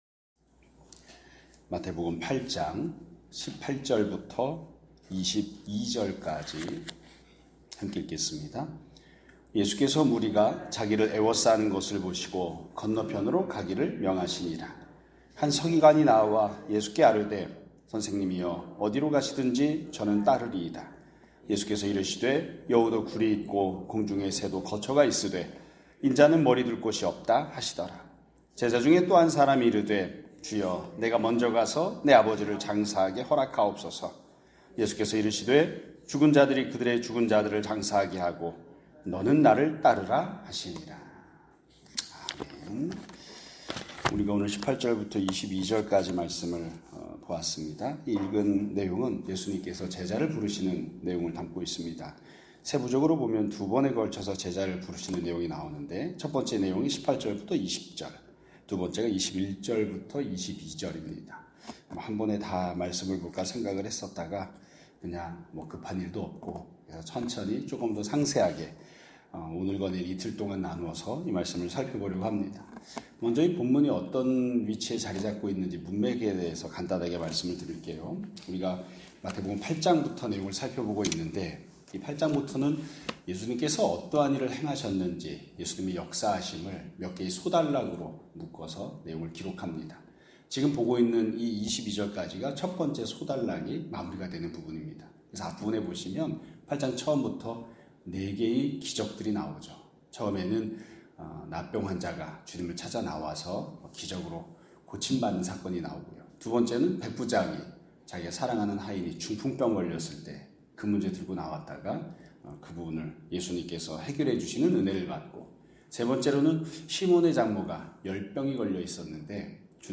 2025년 7월 14일(월요일) <아침예배> 설교입니다.